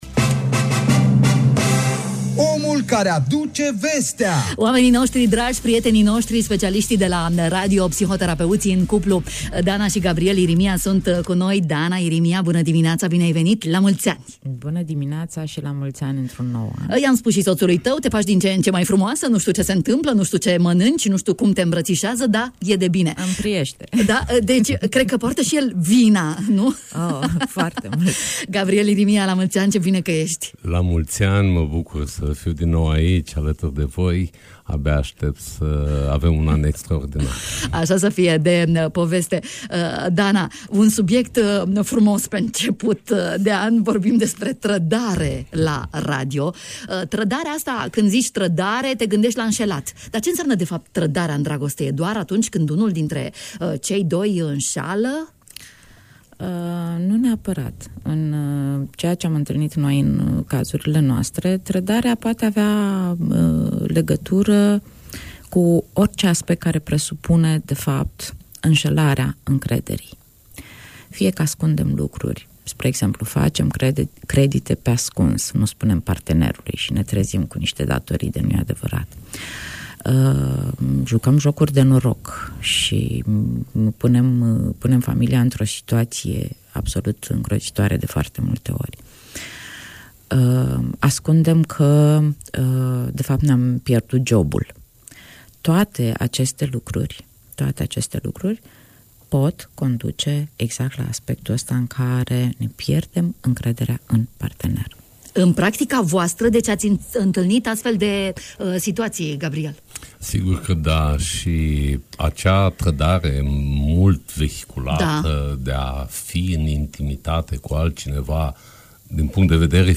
Am aflat de la specialiștii invitați la Radio România Iași: Share pe Facebook Share pe Whatsapp Share pe X Etichete